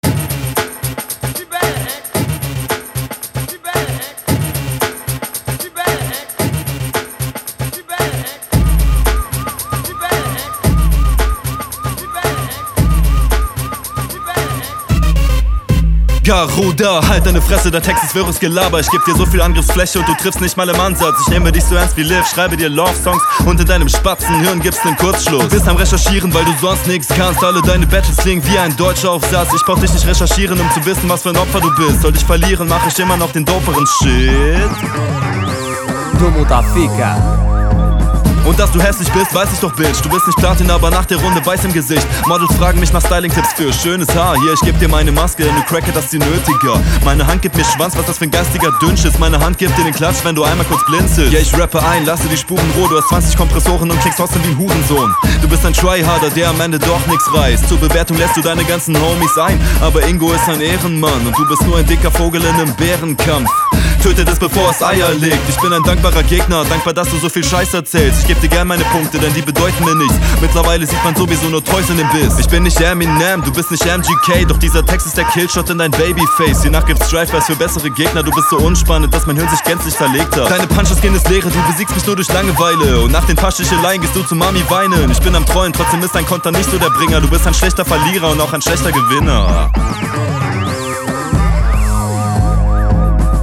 Flow schon gut, aber der Beat ist halt echt Müll und das harmoniert nicht so …